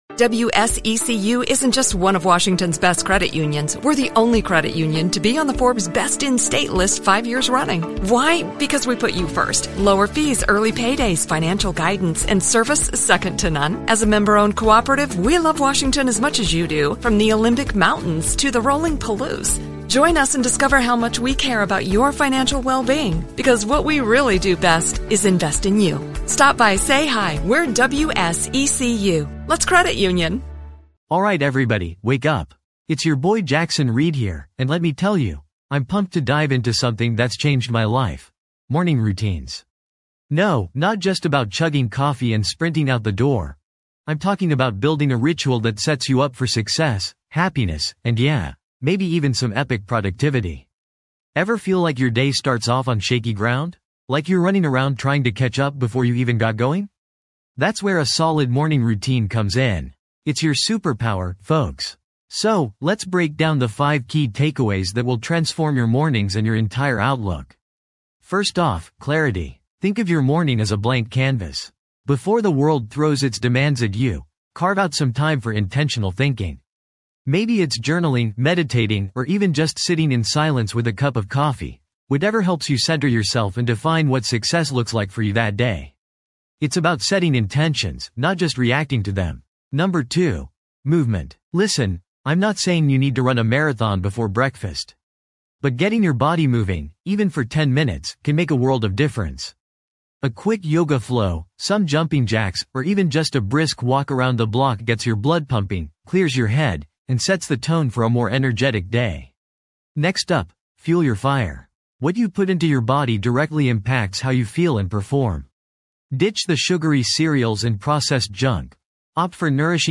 Personal Development, Success Stories, Motivational Speeches, Lifestyle, Productivity Tips
This podcast is created with the help of advanced AI to deliver thoughtful affirmations and positive messages just for you.